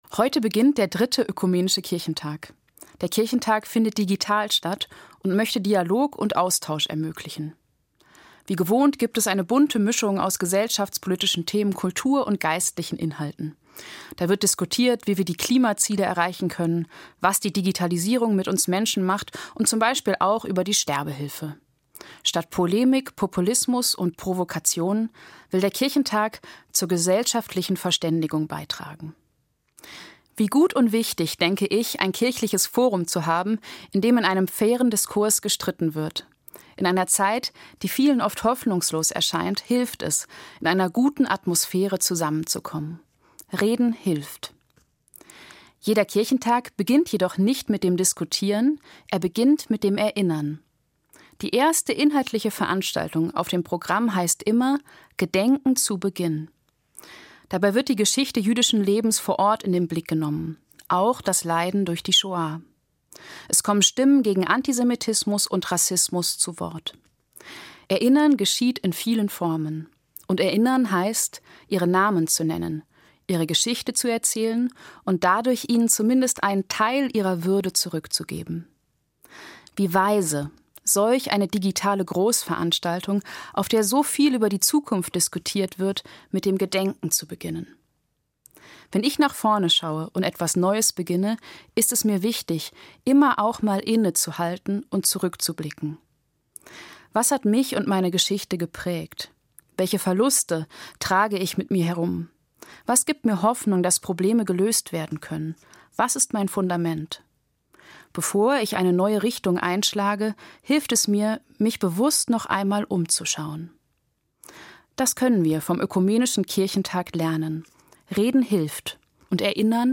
Evangelische Pfarrerin, Bad Vilbel